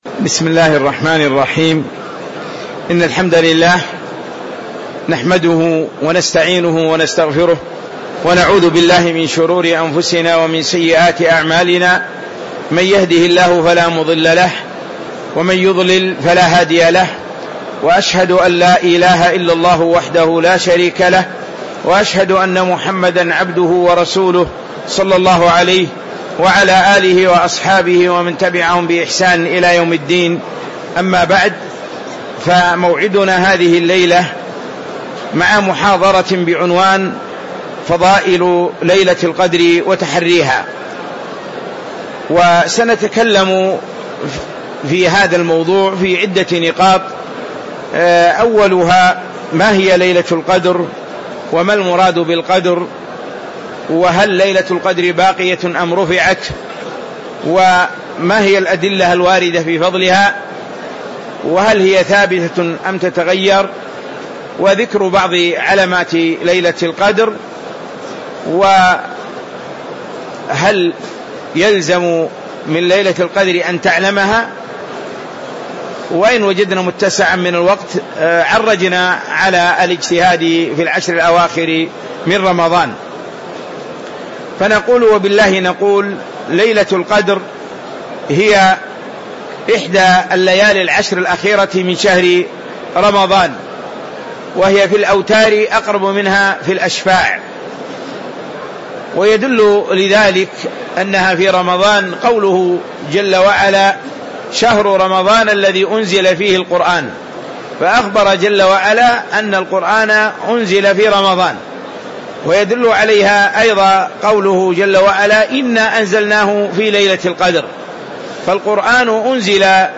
تاريخ النشر ١٩ رمضان ١٤٤٦ هـ المكان: المسجد النبوي الشيخ